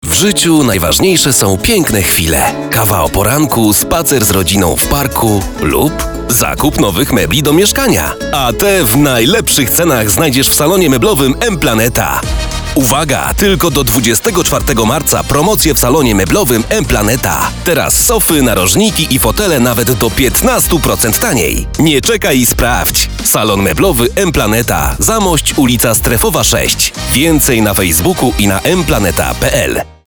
Przykładowe nagrania lektorskie.